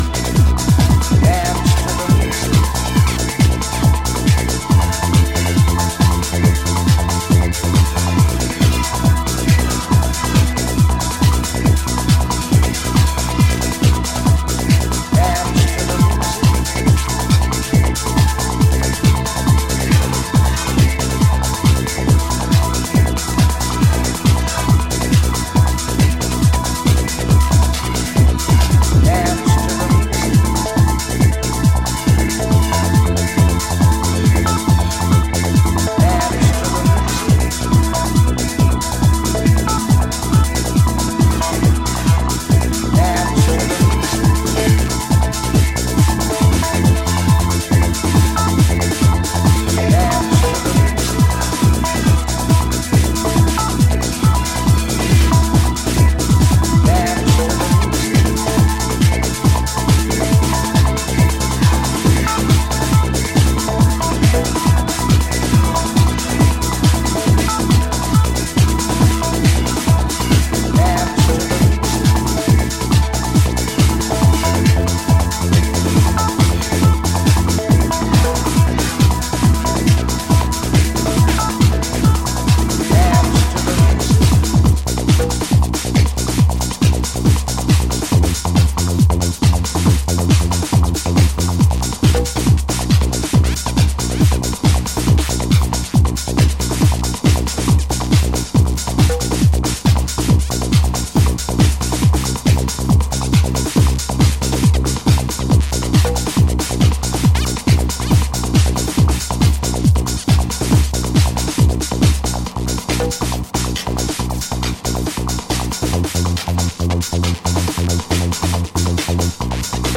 in full club mode
House Techno